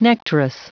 Prononciation du mot nectarous en anglais (fichier audio)
Prononciation du mot : nectarous